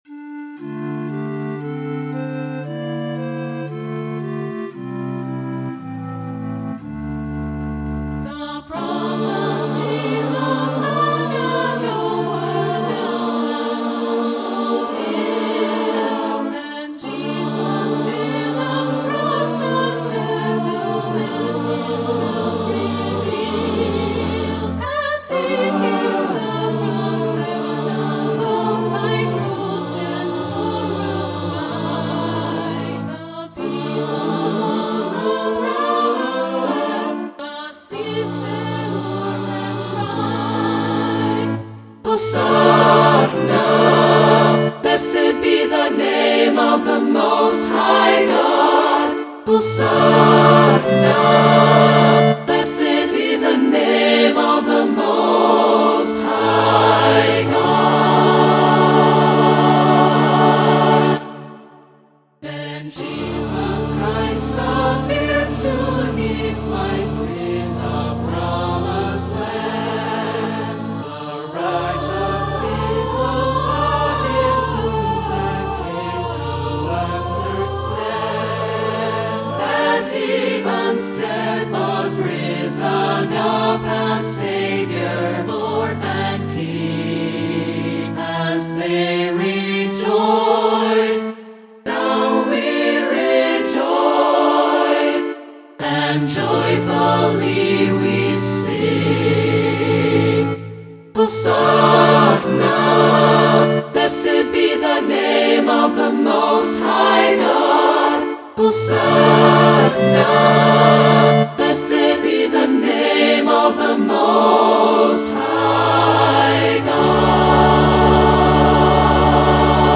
Update 10-29-24: Independent organ part now included.